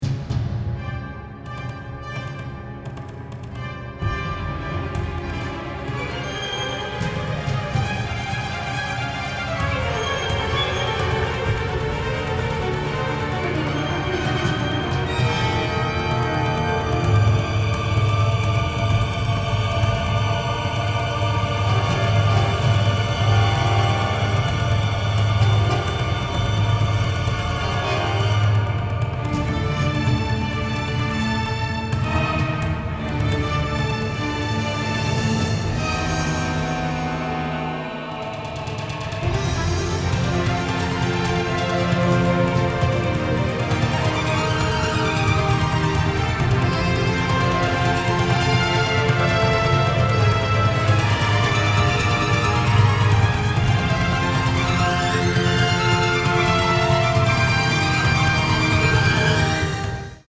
adventure score
Middle Eastern flavor and religious splendor